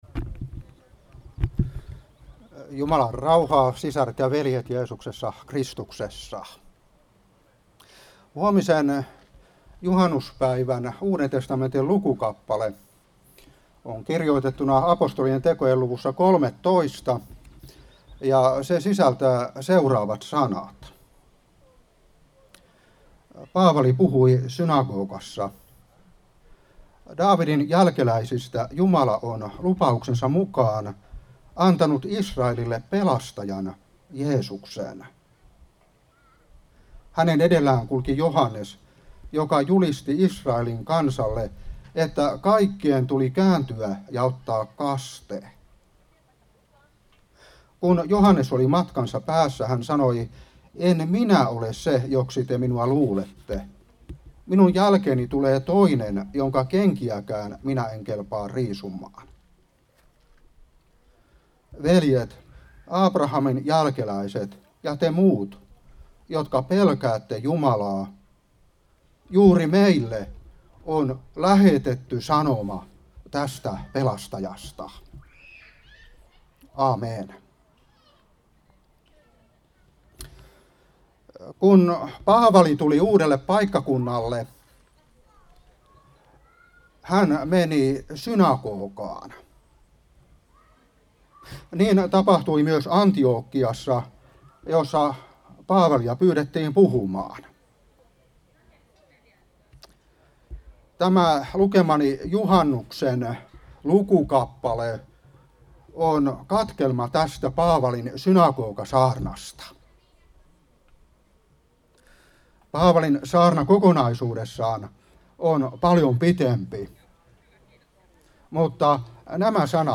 Seurapuhe 2022-6.